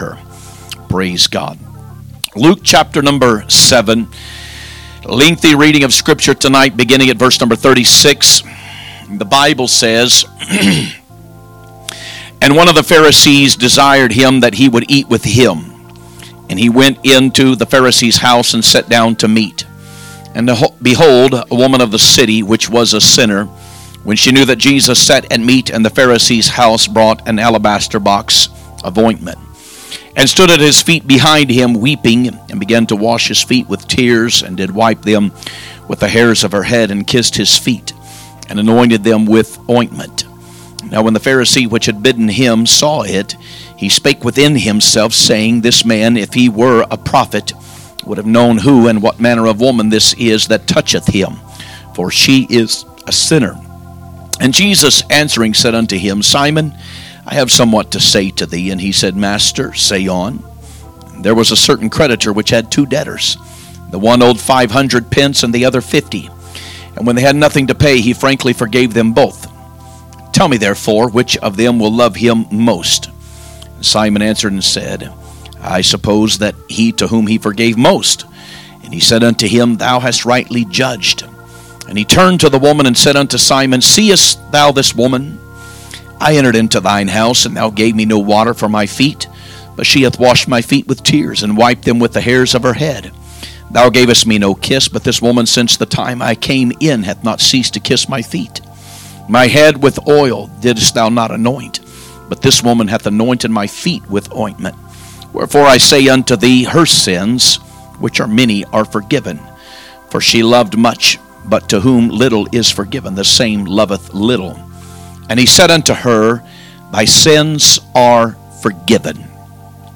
Sunday Evening Message
2025 Sermons